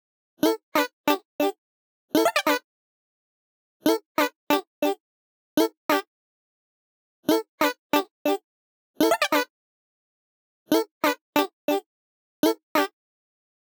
簡単にいうと、音に「広がり」を与えてくれるエフェクターです。